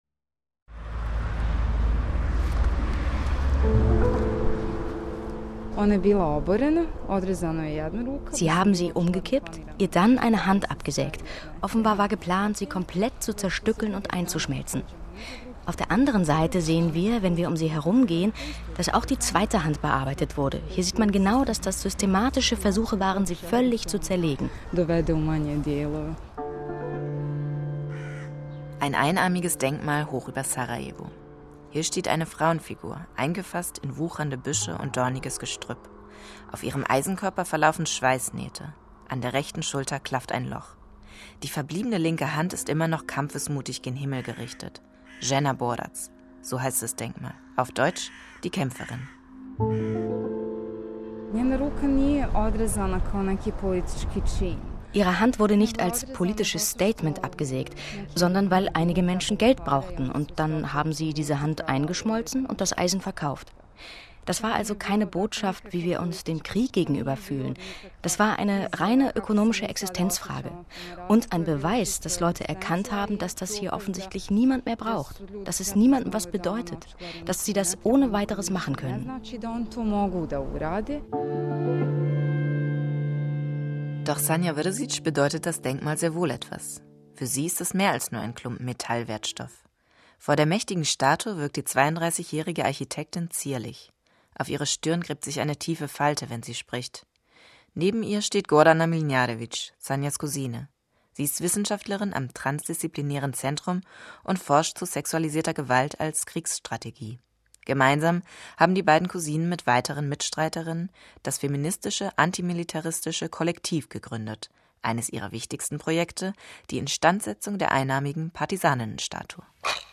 Sendung vom 8.12.2015, rbb-Kulturradio, 25 min